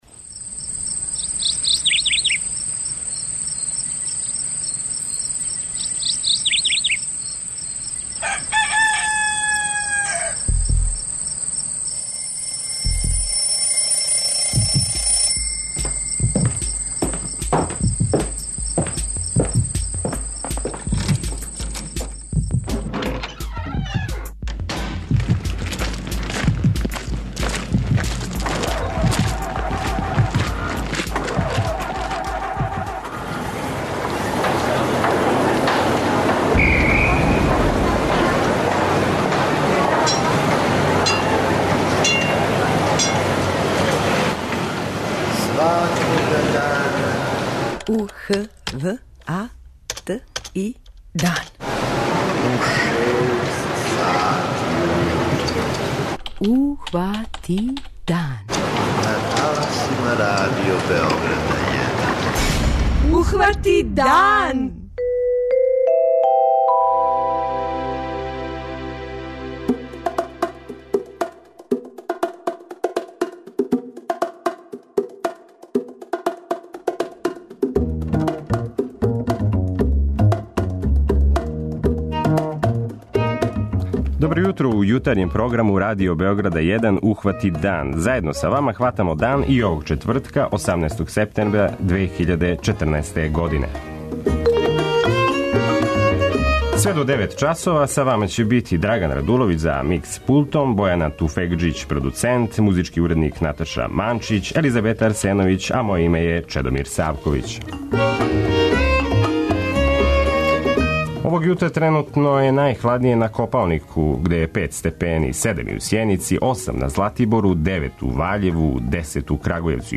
преузми : 85.89 MB Ухвати дан Autor: Група аутора Јутарњи програм Радио Београда 1!